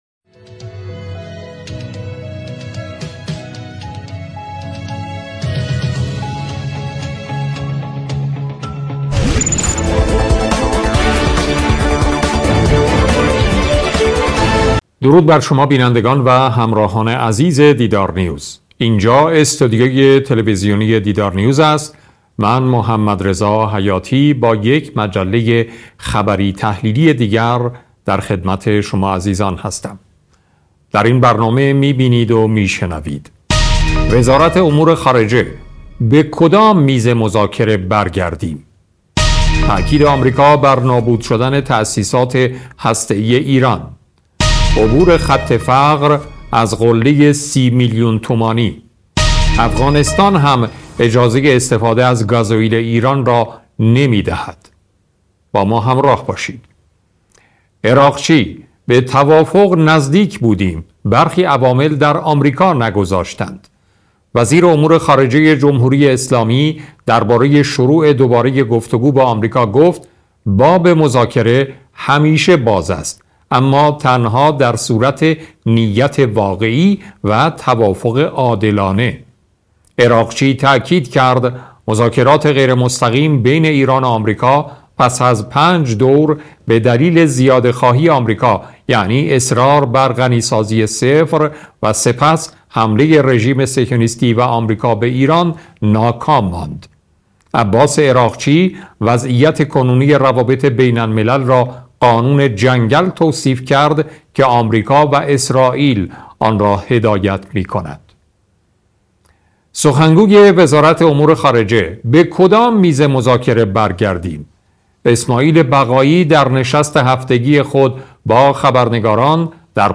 صدای مجله خبری تحلیلی دیدارنیوز با اجرای محمدرضا حیاتی و با حضور کارشناسان و صاحب نظران را می‌توانید اینجا گوش دهید.